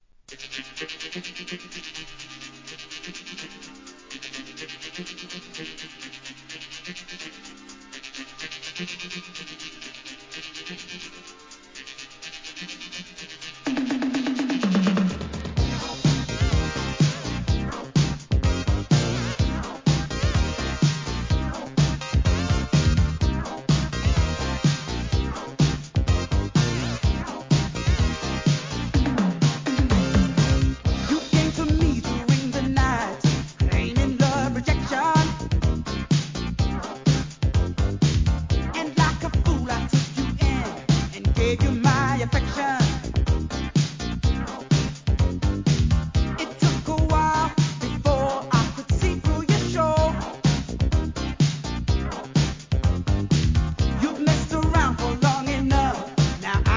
SOUL/FUNK/etc...
エレクトロDISCO